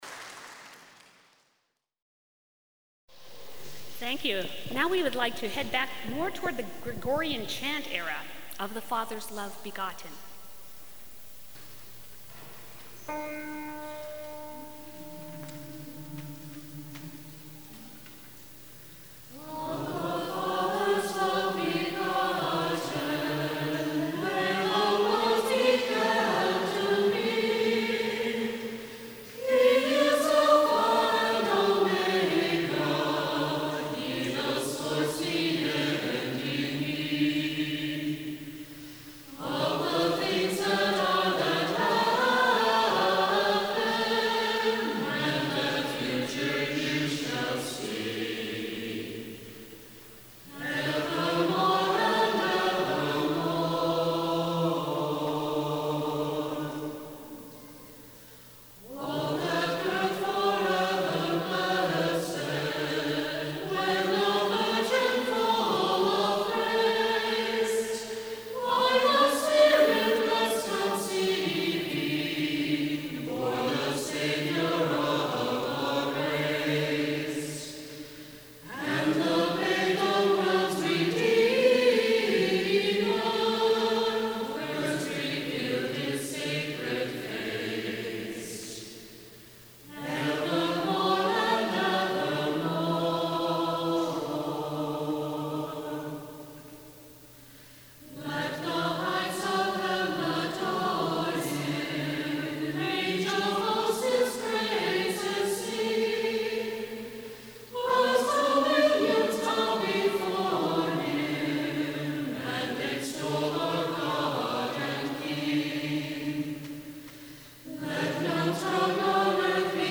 Below is the St. Juan Diego Parish Choir December 2003 performance at the National Sanctuary of our Sorrowful Mother in Portland, Oregon.